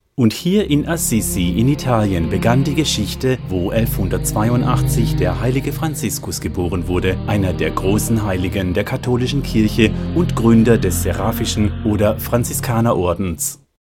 Voice-Over
Audio Guide - German